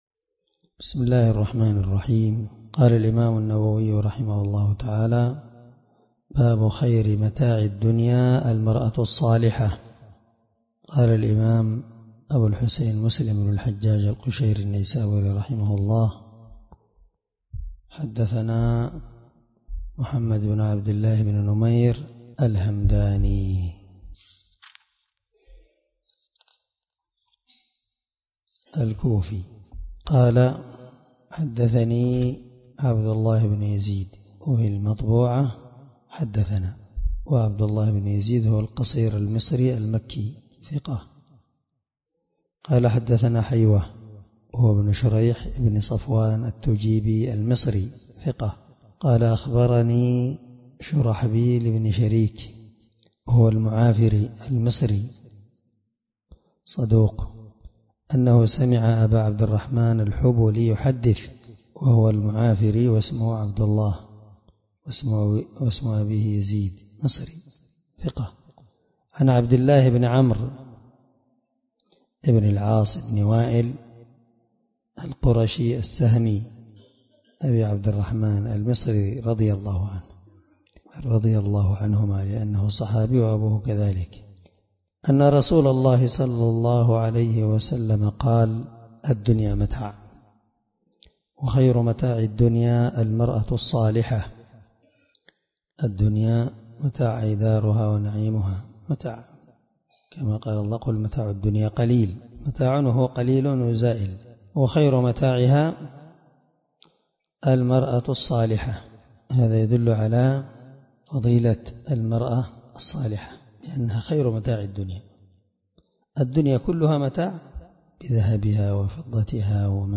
الدرس17من شرح كتاب الرضاع حديث رقم(1467-1469) من صحيح مسلم